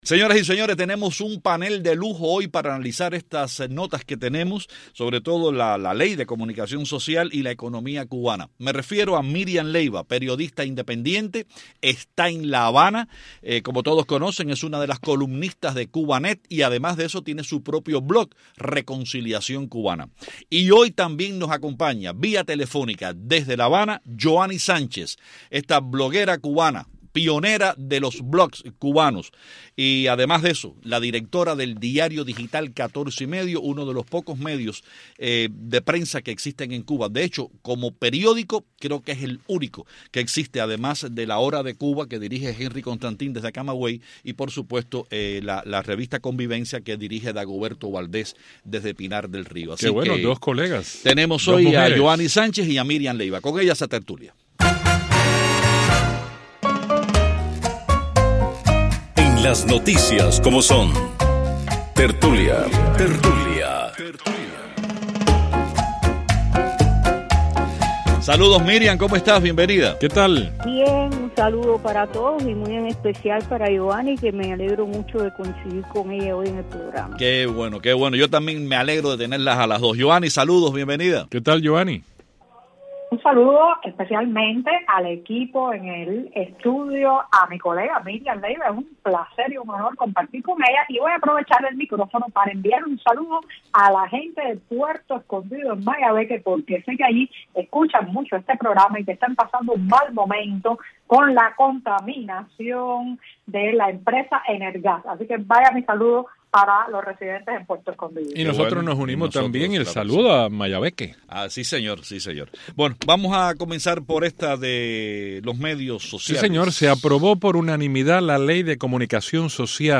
Fragmento de la entrevista